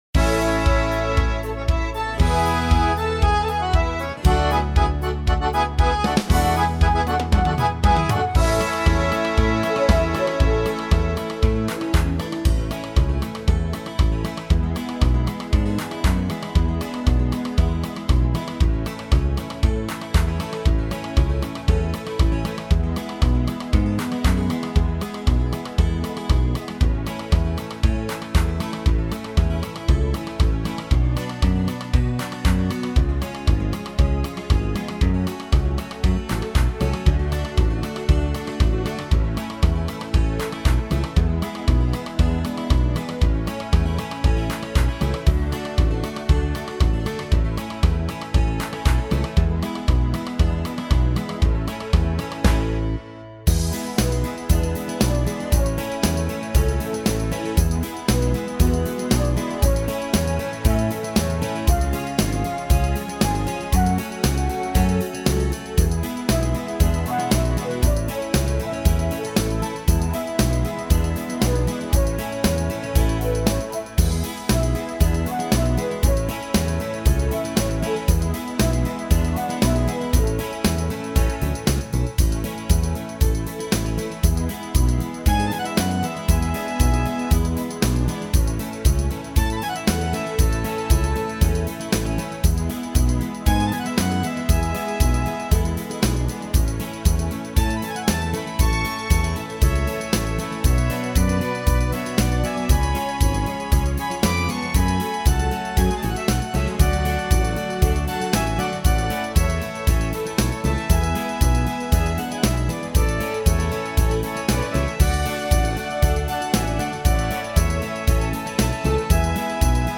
минусовка версия 23881